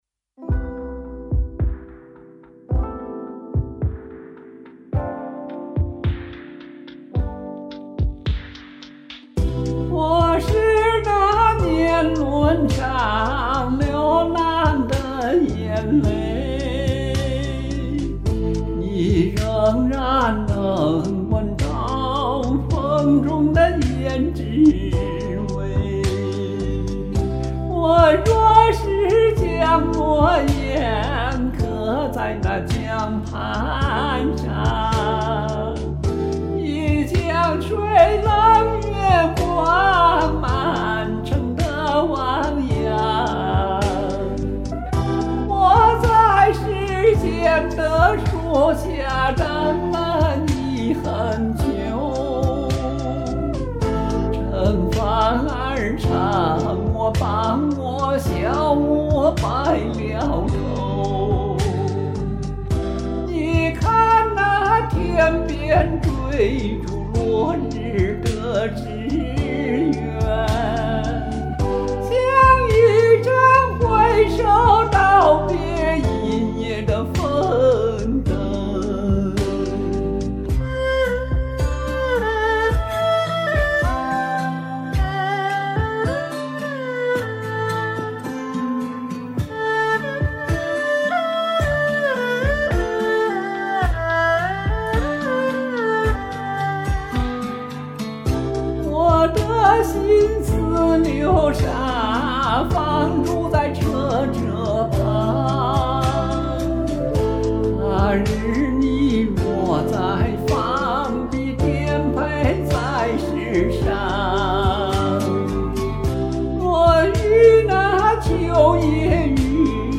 戏曲味道浓， 凄切婉转有韵味的演绎！
听你小嗓子运用自如，音符中有强烈的画面感，棒！
这唱功了得！韵味悠长